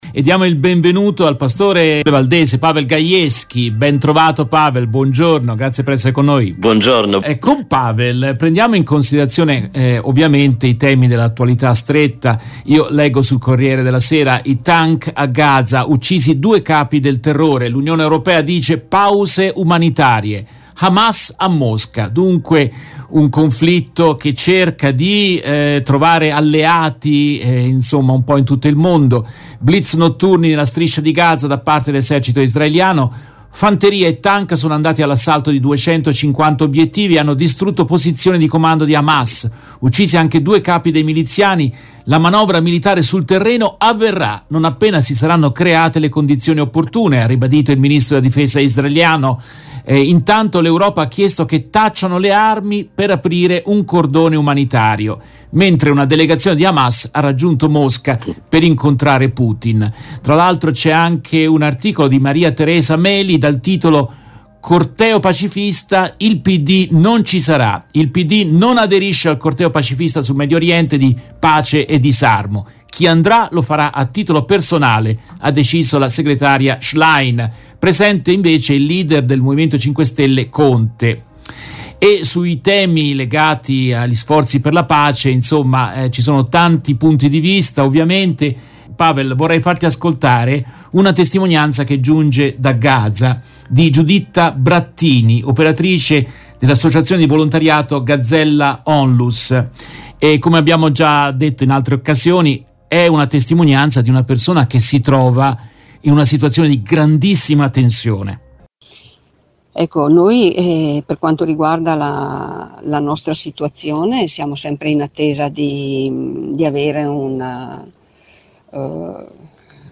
In questa intervista tratta dalla diretta RVS del 27 ottobre 2023